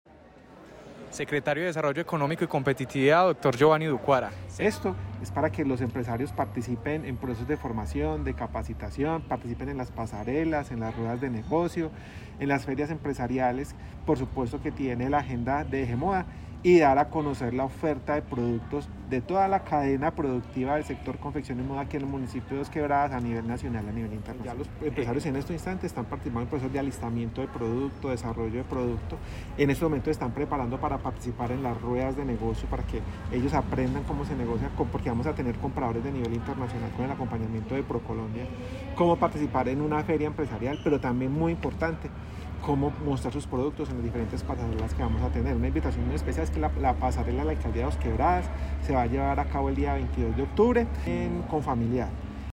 Escuchar Audio: Secretario de Desarrollo Económico y Competitividad, Geovanny Ducuara Londoño.